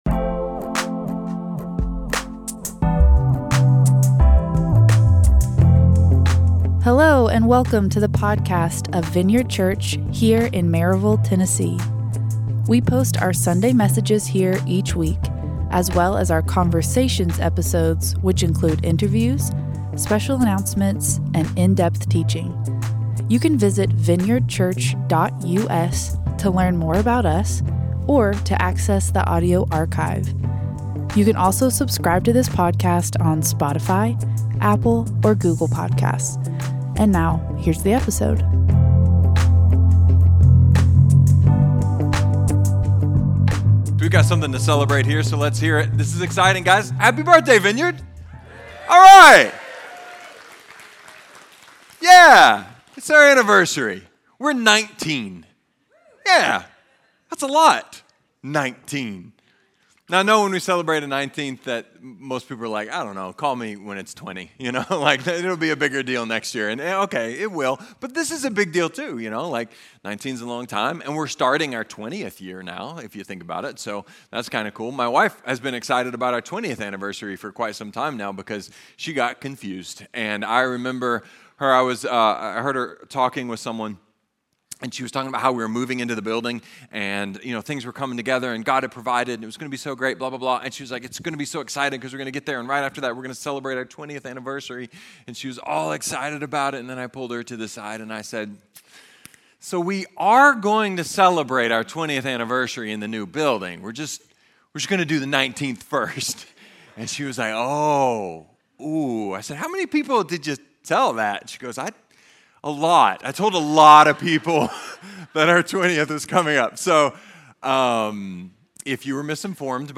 A sermon about diving headlong into the shelter of the King (and also a better, far less dramatic approach).